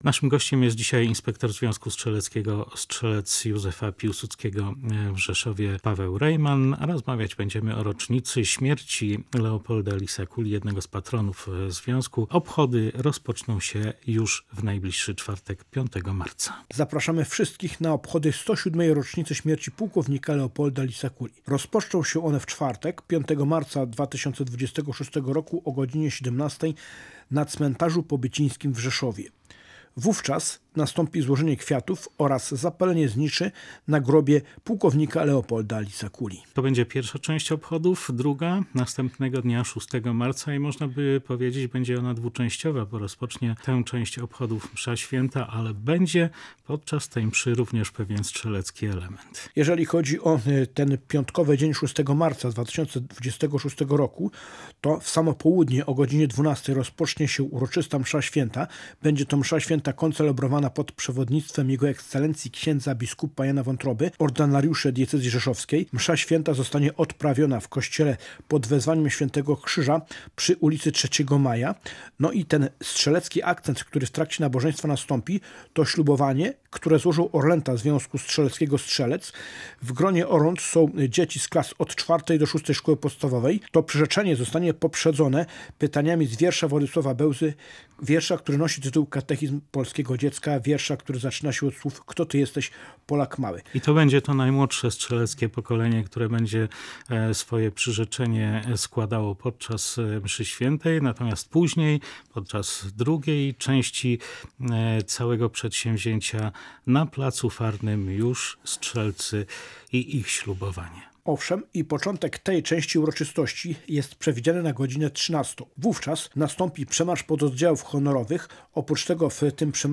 O czwartkowych uroczystościach opowiadał w poranny programie „Kalejdoskop”